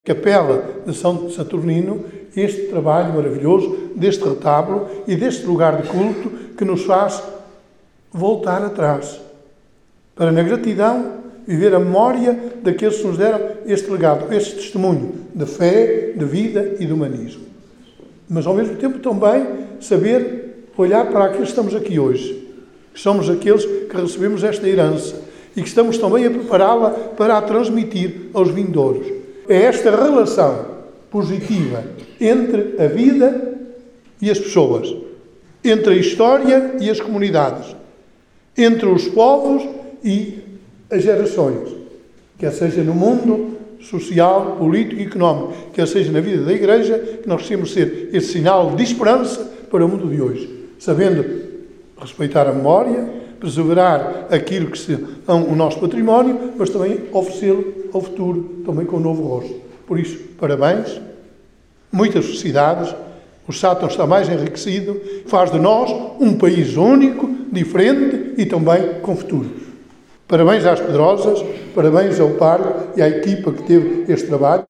Nesta segunda-feira, 19 de janeiro, na localidade de Pedrosas, no concelho de Sátão, foram inauguradas as obras de restauro do Altar Mor da Capela de São Saturnino, numa cerimónia que contou com a presença de Alexandre Vaz, Presidente da Câmara Municipal de Sátão, Margarida Balseiro Lopes, Ministra da Cultura, Juventude e Desporto e de D. António Luciano, Bispo de Viseu, entre outras entidades convidadas.
D. António Luciano, Bispo de Viseu, ao falar do restauro do Altar Mor da Capela de Saturnino, disse que o concelho de Sátão está mais enriquecido, “sabendo respeitar a memória…, oferecê-lo ao futuro com um novo rosto…”.
Bispo-de-Viseu.mp3